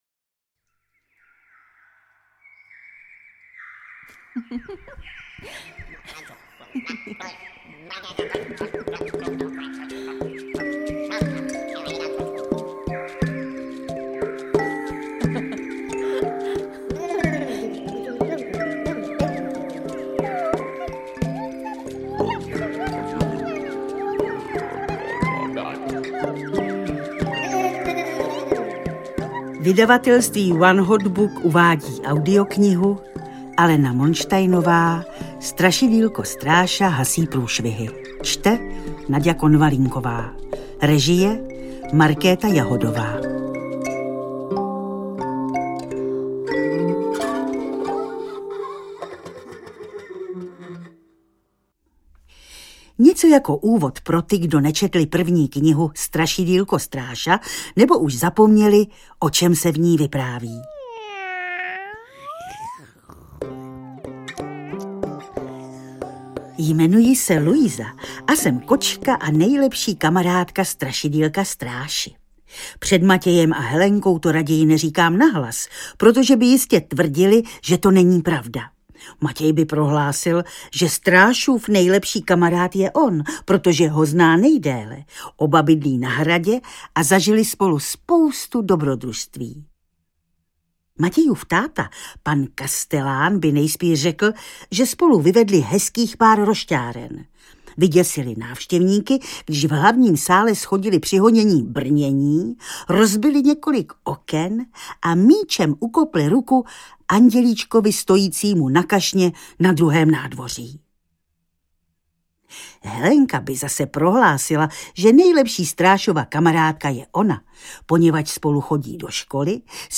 Interpret:  Naďa Konvalinková